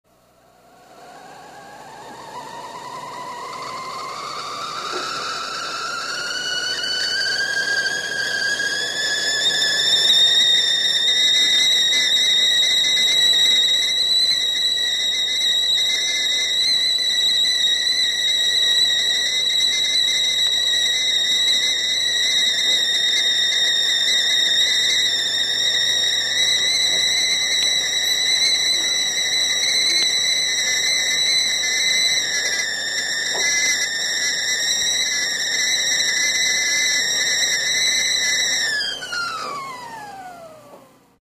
Звуки кипящего чайника
Шум кипящего чайника со свистком